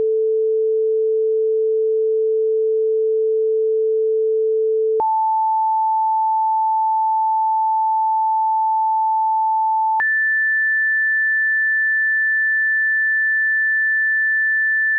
Petit exemple, un La440 est donné pour un signal de fréquence 440Hz. Si on veut la même note une octave au dessus, il faut doubler la fréquence, ce qui nous donne un La à 880Hz. Pareil, si on veut encore augmenter d'une octave, on redouble la fréquence et on tombe sur 1660Hz.
Voila un petit exemple sonore pour ces trois fréquence :
Ainsi, les trois La nous semblent espacés du même écart, alors que ce n'est pas vrai en fréquence : ils sont espacé d'un même rapport !
octave.mp3